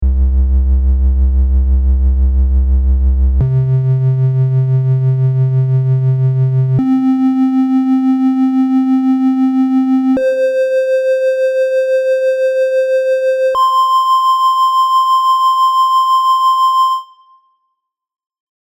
While the same oscillator creates a tremolo effect when applied to amplitude.
Tri-Trem-6hz_-3dB.mp3